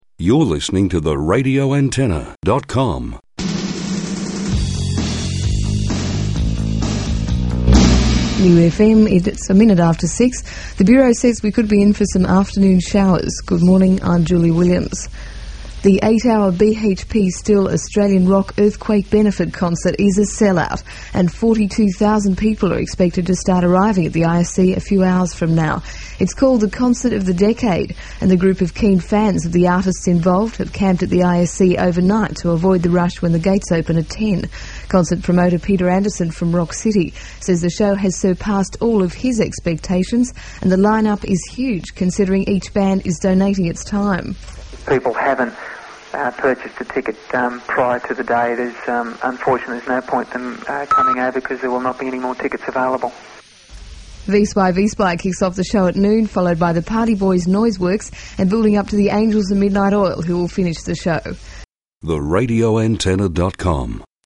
RA Aircheck